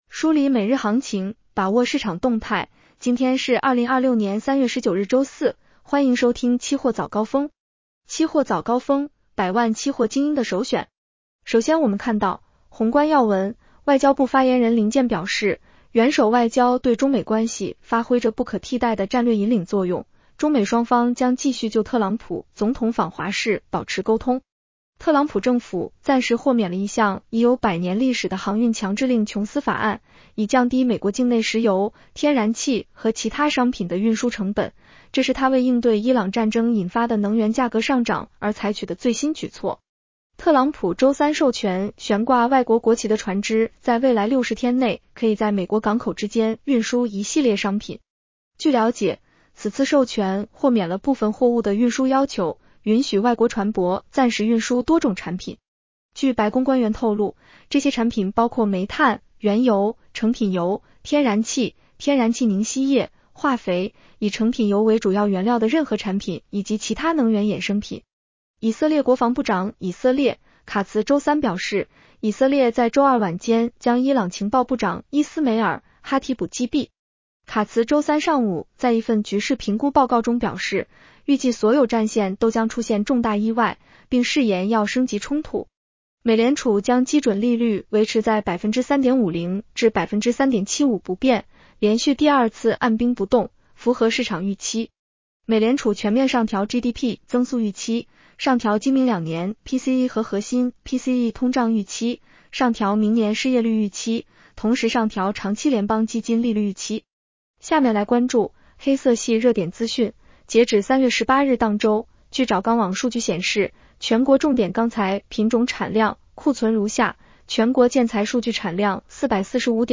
期货早高峰-音频版 女声普通话版 下载mp3 热点导读 1.特朗普政府暂时豁免了一项已有百年历史的航运强制令《琼斯法案》，以降低美国境内石油、天然气和其他商品的运输成本，这是他为应对伊朗战争引发的能源价格上涨而采取的最新举措。